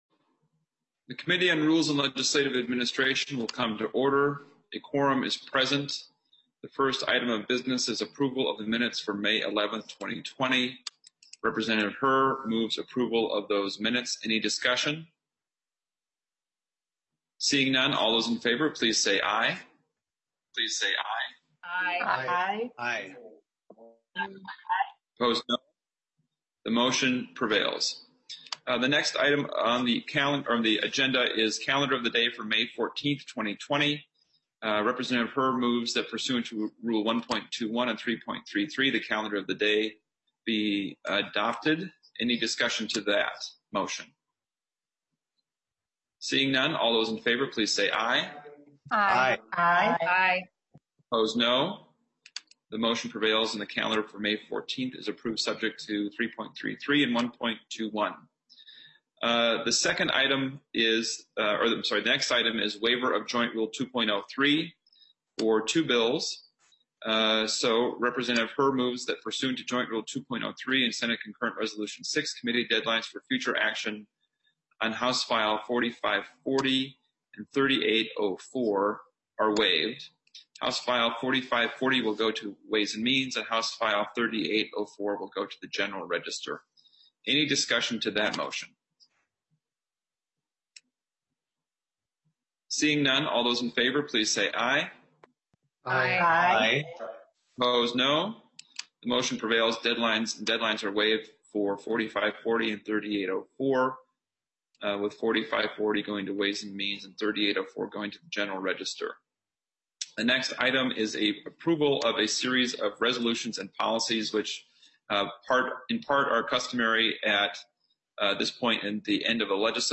Chair: Rep. Ryan Winkler
This remote hearing may be viewed live via the following methods: